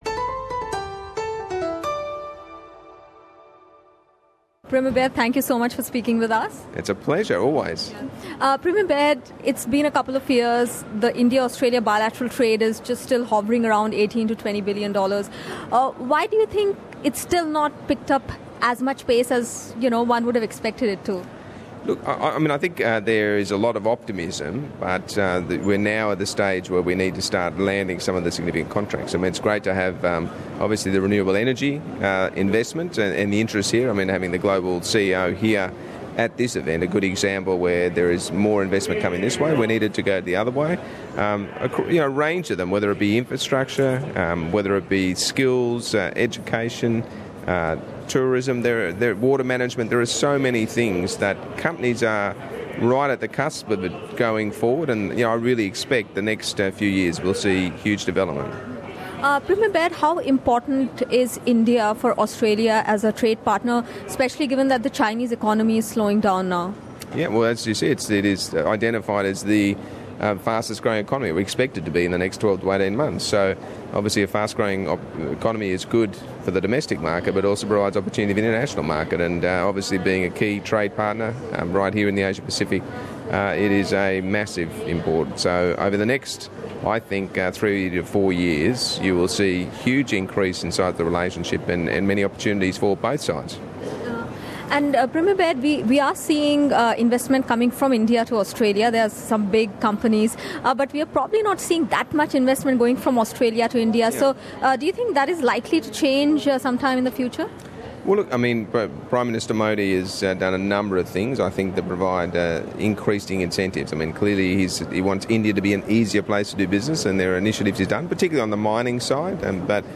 NSW Premier Mike Baird spoke specially to SBS Hindi at the recent AIBC Annual Dinner. Premier Baird emphasized the importance of Australia-India relations and mentioned that the two countries were strong friends.